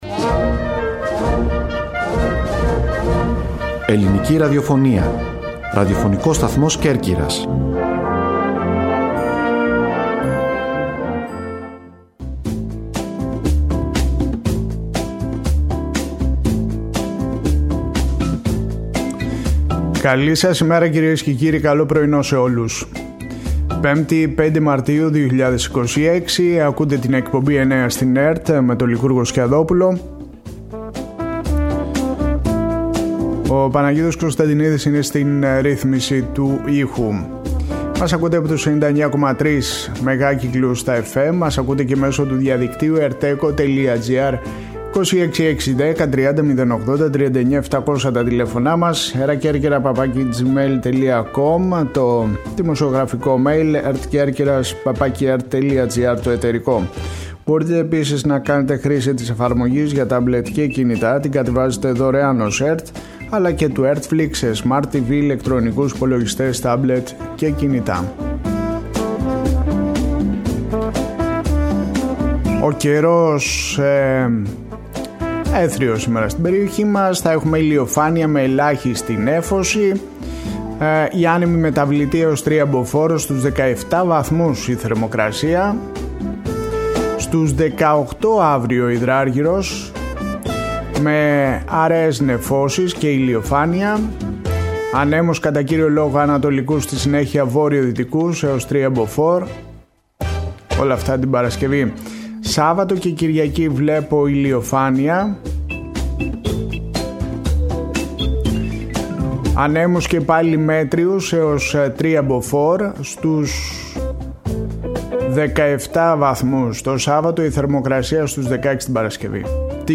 Στη σημερινή εκπομπή, ακούγονται αποσπάσματα από τις δηλώσεις του Περιφερειάρχη Ιονίων Νήσων Γιάννη Τρεπεκλή για την έναρξη του οδικού έργου Βρυώνη – Μωραϊτικα αλλά και του δημάρχου Παξών Σπ. Βλαχόπουλου για την προγραμματική σύμβαση που υπεγράφη με την Περιφέρεια.
«Εννέα στην ΕΡΤ» Οριοθέτηση της ειδησιογραφίας στην Κέρκυρα, την Ελλάδα και τον κόσμο, με συνεντεύξεις, ανταποκρίσεις και ρεπορτάζ.